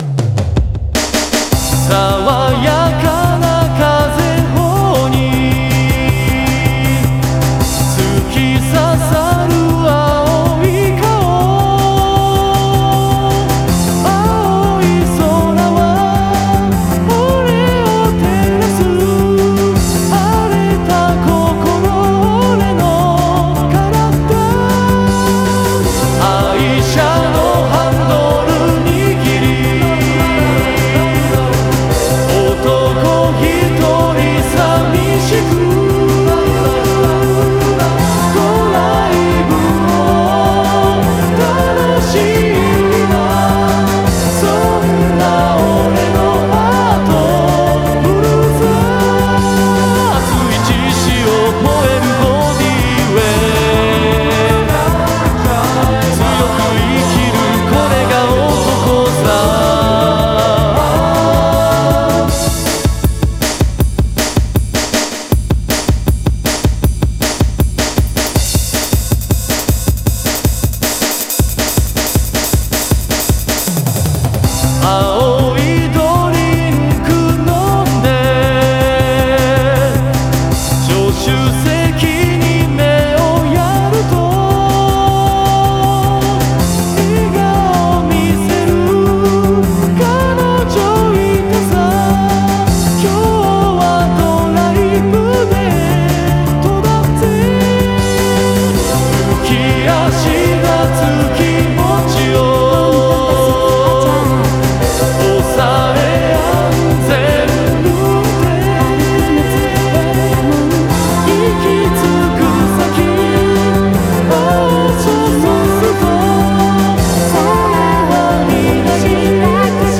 近年の合成音声はクオリティが高く、まるで人間のように自然な声で歌います。
Vocal Synthesizer
バブリーな時代を彷彿とさせる歌です。